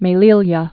(mā-lēlyä)